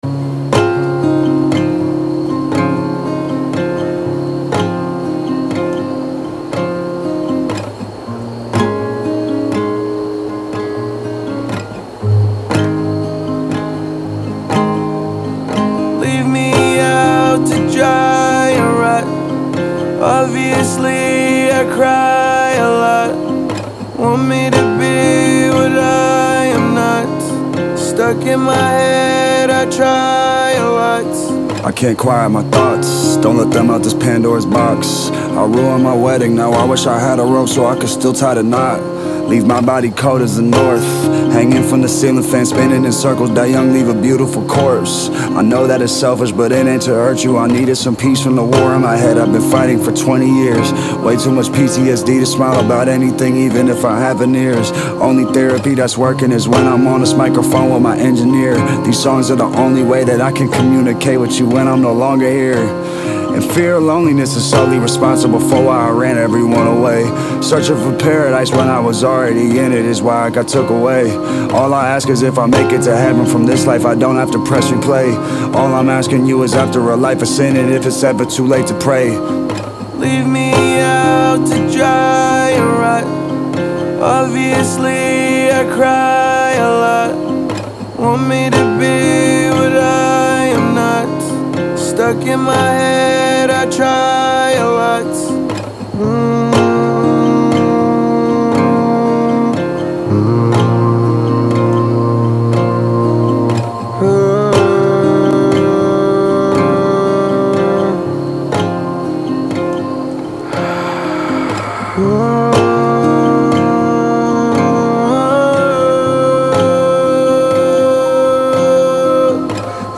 • Жанр: Hip-Hop